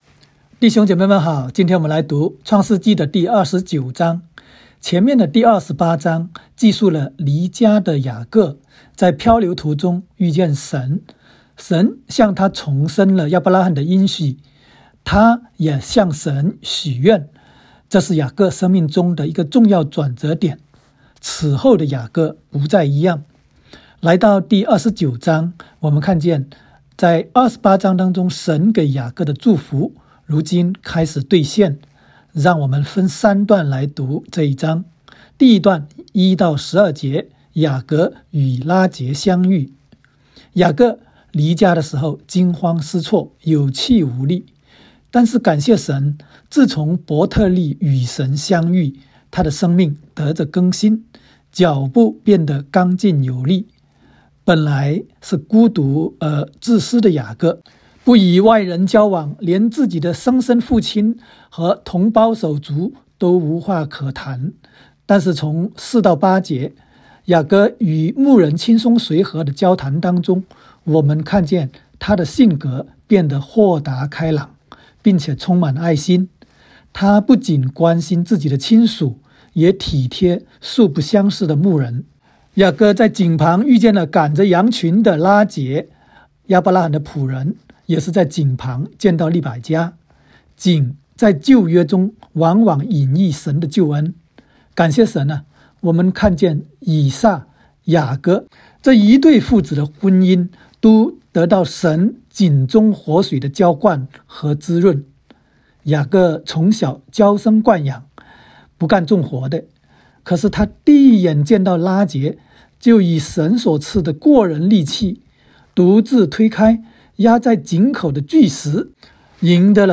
创29（讲解-国）.m4a